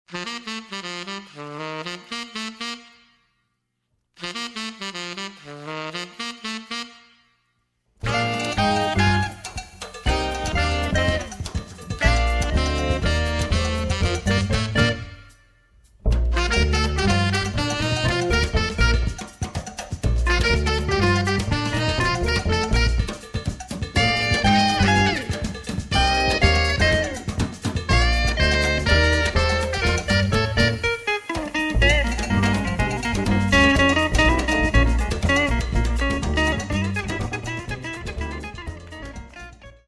7人編成の緻密なアンサンブルが繰り広げるブラジリアン・グルーヴは、煎り立ての極上ブレンド・ミュージック！！